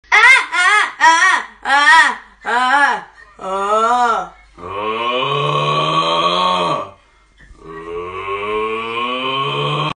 Sound Effects
Goofy Ahh Sounds!